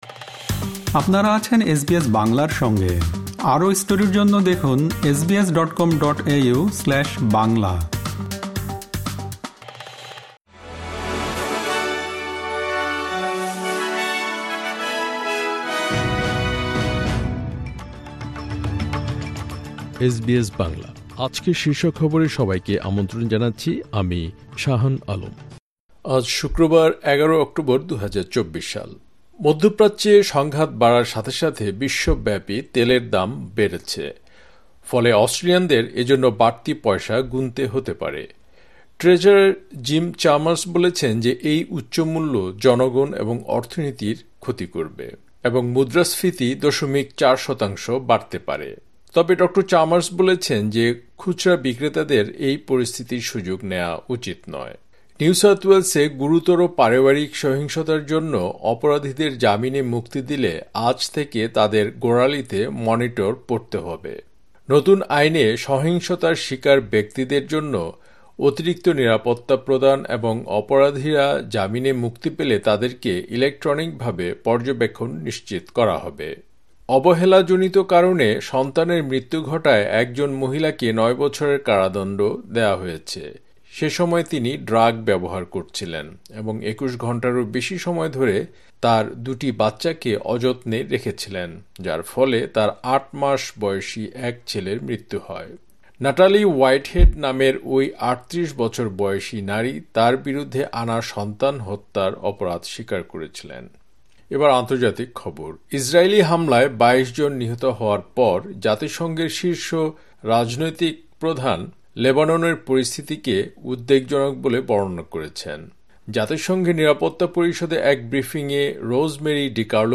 আজকের শীর্ষ খবর মধ্যপ্রাচ্যে সংঘাত বাড়ার সাথে সাথে বিশ্বব্যাপী তেলের দাম বেড়েছে, ফলে অস্ট্রেলিয়ানদের এজন্য বাড়তি পয়সা গুনতে হতে পারে। ইসরায়েলি হামলায় ২২ জন নিহত হওয়ার পর, জাতিসংঘের শীর্ষ রাজনৈতিক প্রধান লেবাননের পরিস্থিতিকে উদ্বেগজনক বলে বর্ণনা করেছেন। বাংলাদেশের এক পূজামণ্ডপে ইসলামি সংগীত পরিবেশন নিয়ে বিতর্ক সৃষ্টি হলে ৪৮ ঘণ্টার মধ্যে জড়িতদের গ্রেপ্তারের আশ্বাস দিয়েছেন দেশটির একজন সরকারি কর্মকর্তা।